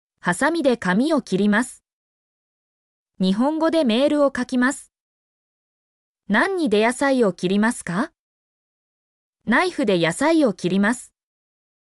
mp3-output-ttsfreedotcom-7_vBV5rnZr.mp3